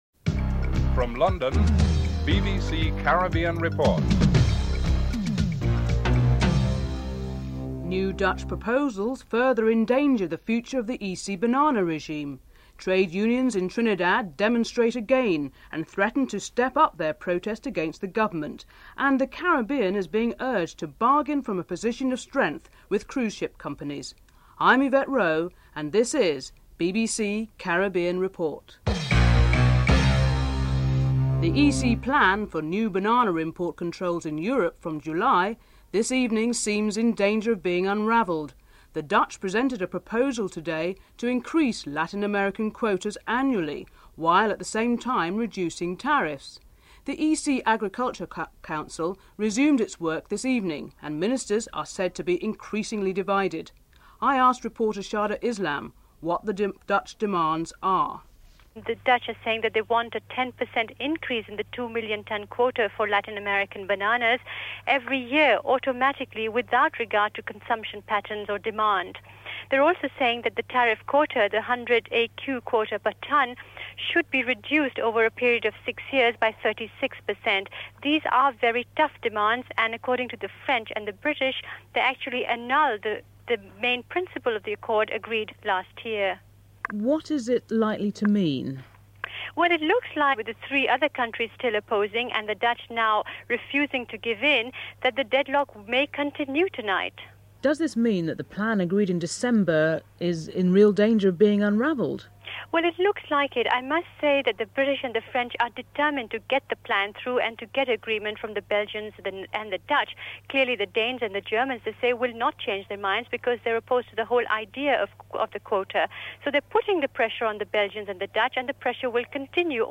The Caribbean is now regarded as the world’s prime cruise sector.